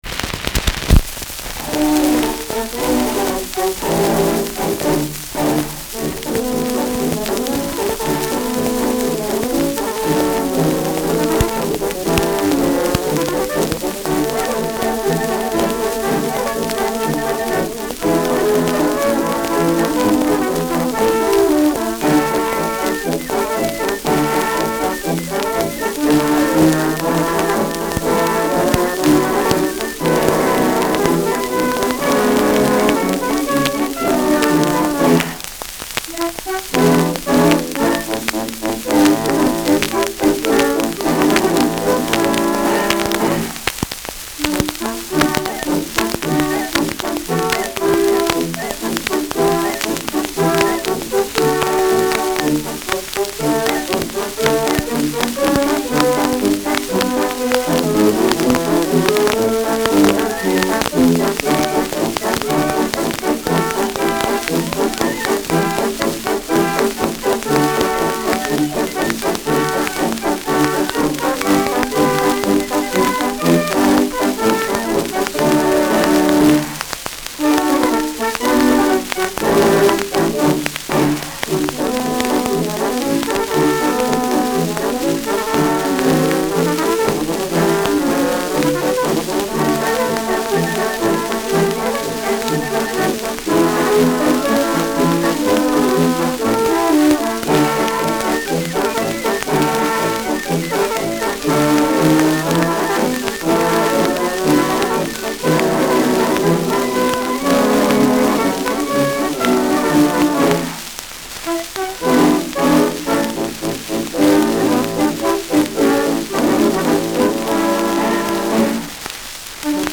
Schellackplatte
Stark abgespielt : Starkes Grundrauschen : Teils sehr starkes Knacken durch Kratzer
Beka-Orchester (Interpretation)
Operettenmelodie* FVS-00011